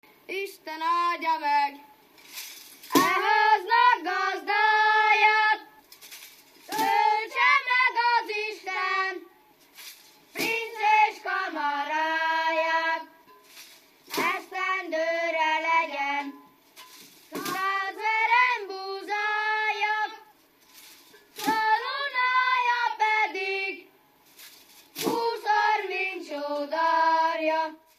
Felföld - Abaúj-Torna vm. - Fáj
Stílus: 8. Újszerű kisambitusú dallamok
Kadencia: 2 (2) 3 1